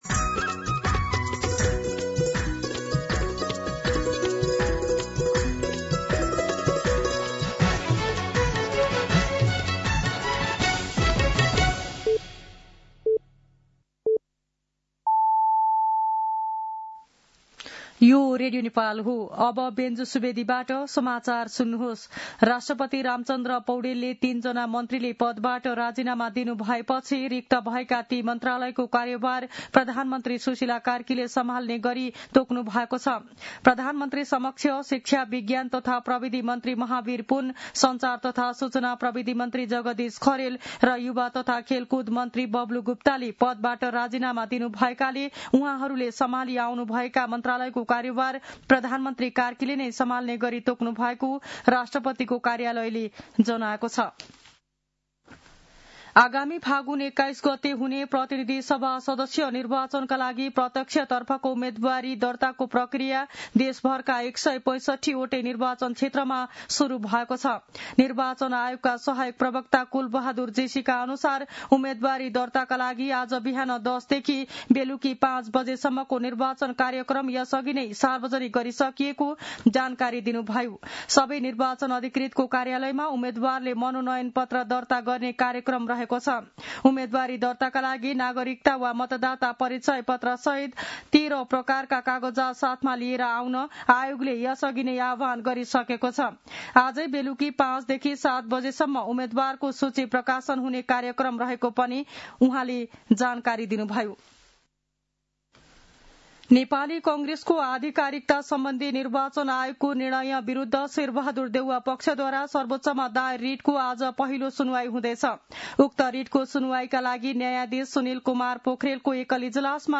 दिउँसो १ बजेको नेपाली समाचार : ६ माघ , २०८२